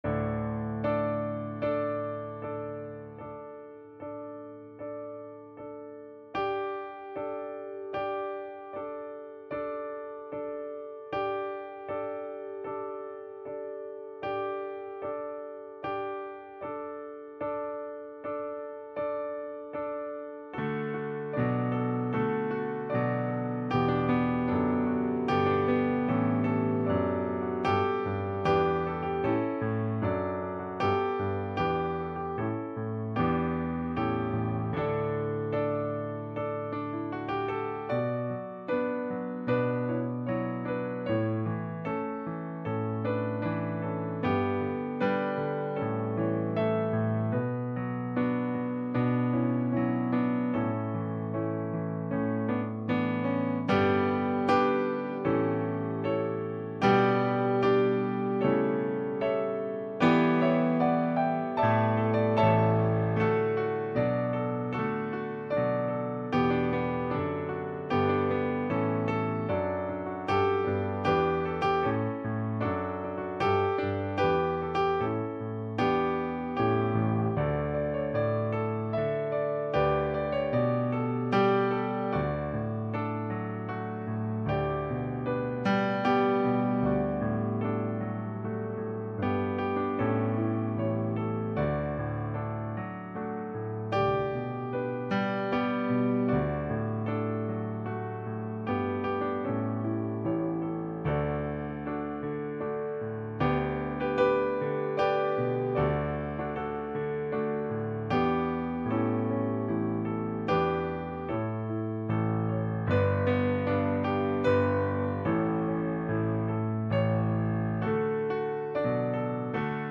Elementary School Honor Choir Recording Tracks